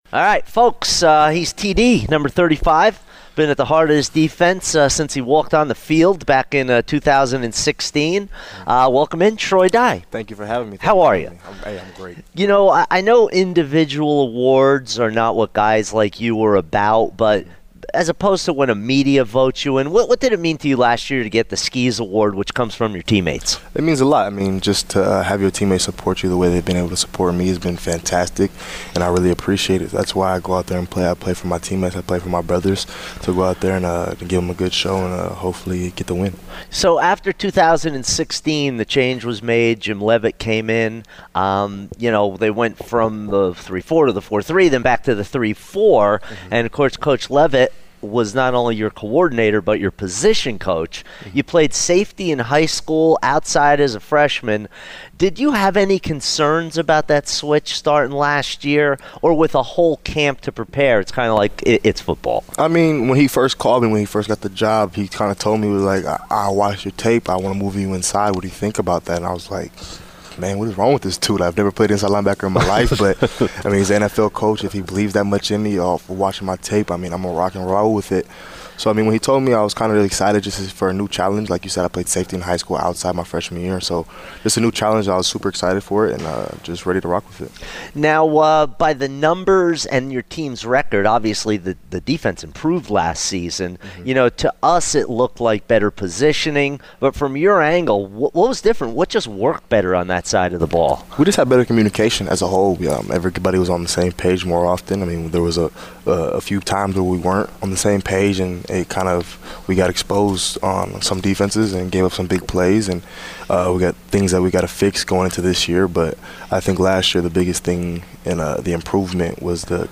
Sports Talk - Troy Dye Interview, Oregon Media Day 2018